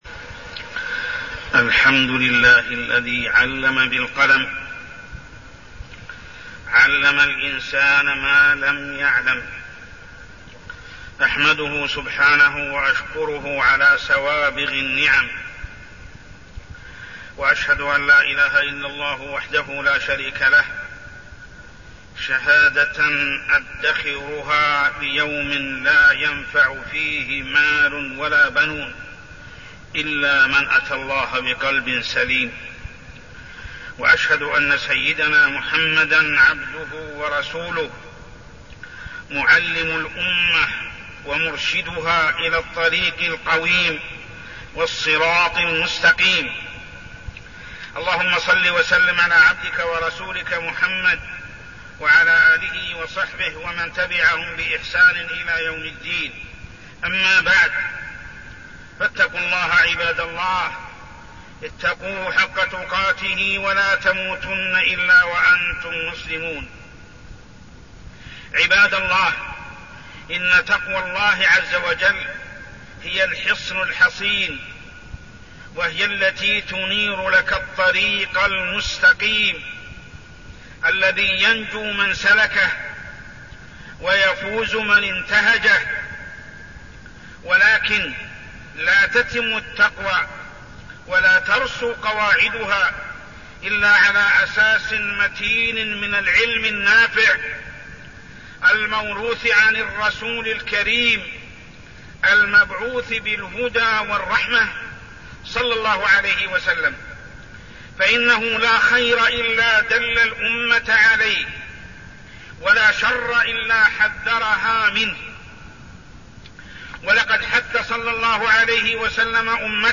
تاريخ النشر ١٣ ربيع الثاني ١٤١٣ هـ المكان: المسجد الحرام الشيخ: محمد بن عبد الله السبيل محمد بن عبد الله السبيل العلم و العمل